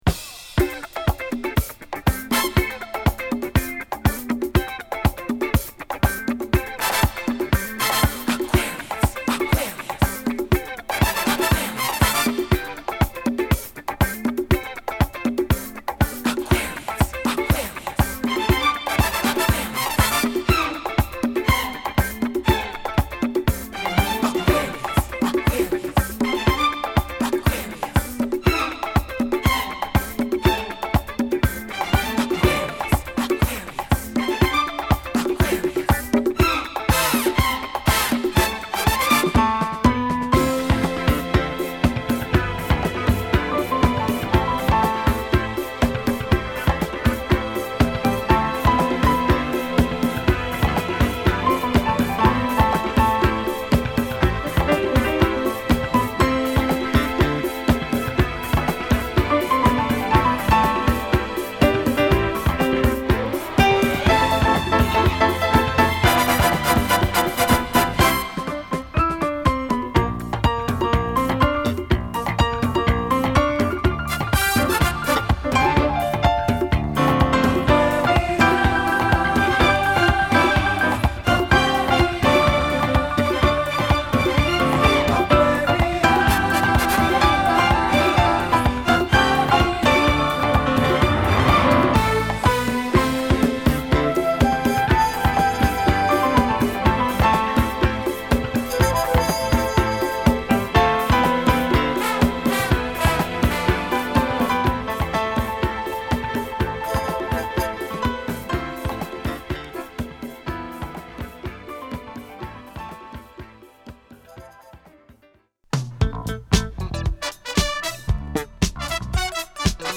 アルバム通して軽やかなフュージョン〜ディスコが楽しめます。